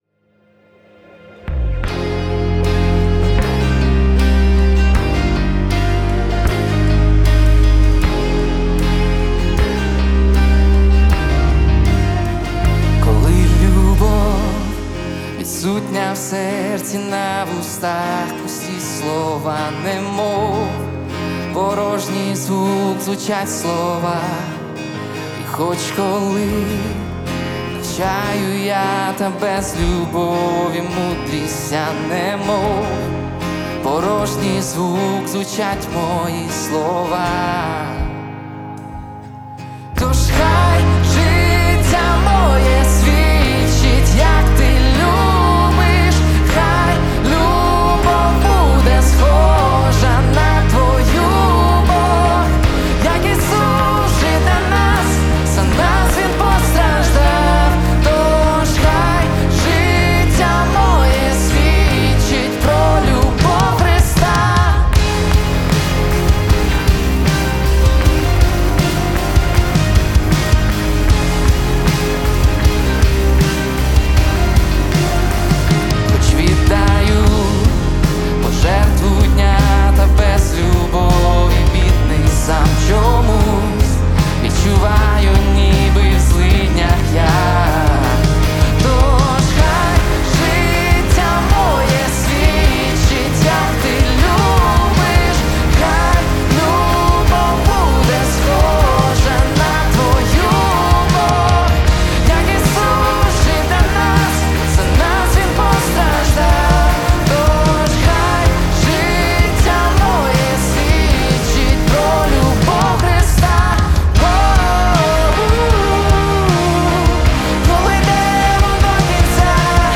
245 просмотров 23 прослушивания 1 скачиваний BPM: 78